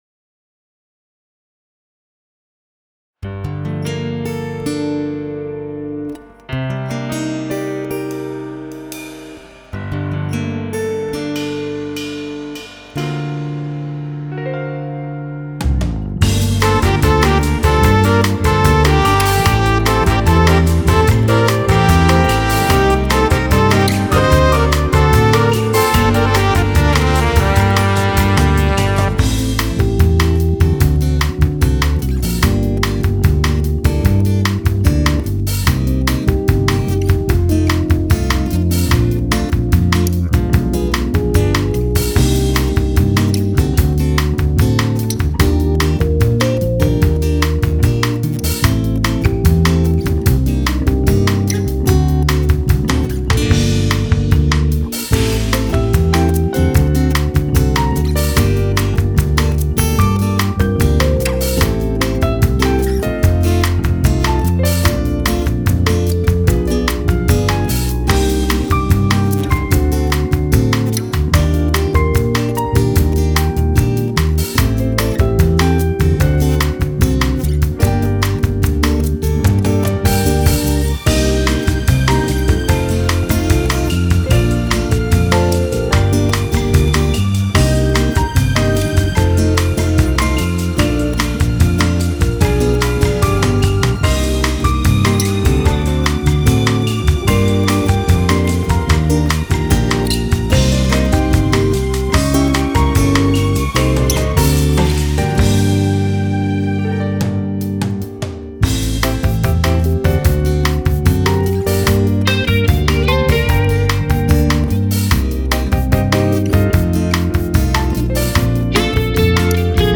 Категория: Рождественские
Скачать фонограмму (10.54 Mb) ]